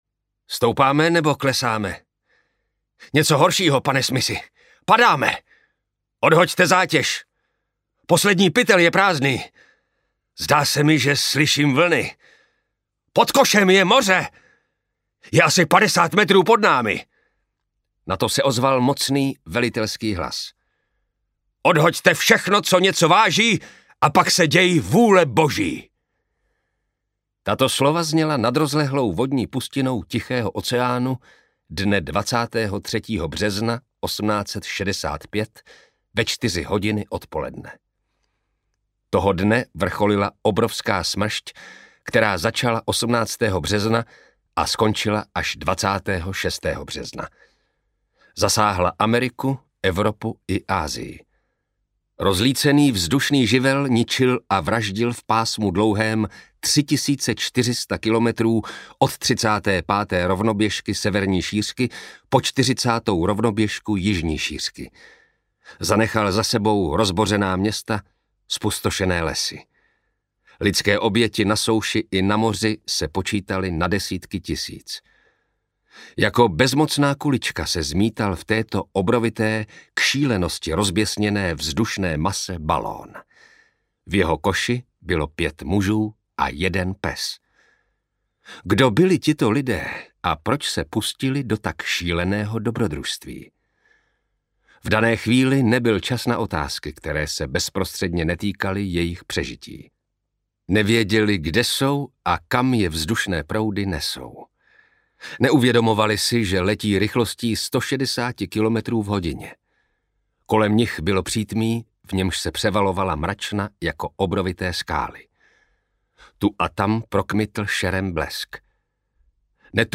Audio kniha
Ukázka z knihy
Vyrobilo studio Soundguru.